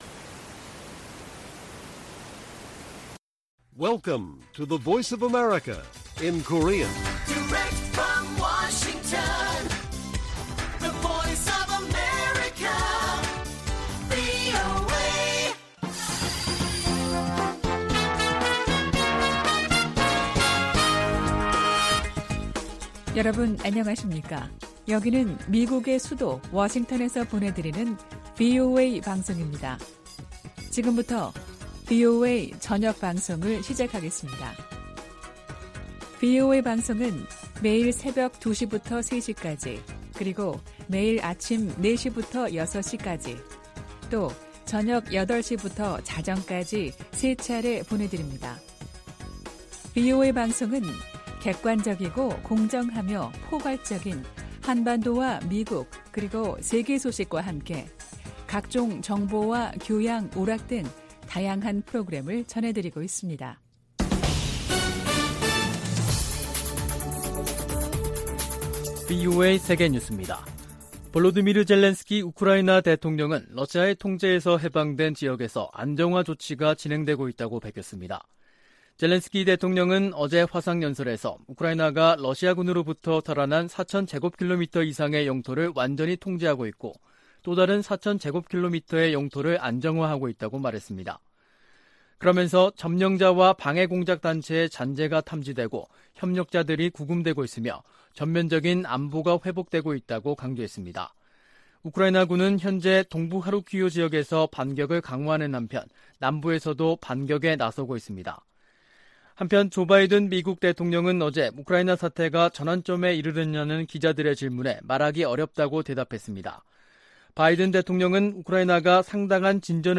VOA 한국어 간판 뉴스 프로그램 '뉴스 투데이', 2022년 9월 14일 1부 방송입니다. 북한의 핵 무력정책 법제화가 유일지배체제의 근본적 모순을 드러내고 있다고 전문가들이 분석하고 있습니다. 미 상원의원들이 북한의 핵 무력정책 법제화에 우려와 비판의 목소리를 내고 있습니다. 유엔 인권기구가 북한 지도부의 코로나 규제 조치로 강제노동 상황이 더 악화했을 수 있다고 경고했습니다.